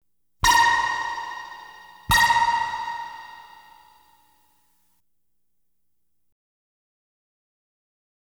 Light Beam Hits Sound Effect
light-beam-hits-2.wav